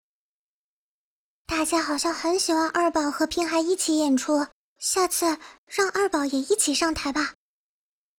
贡献 ） 协议：Copyright，人物： 碧蓝航线:平海语音 2021年2月4日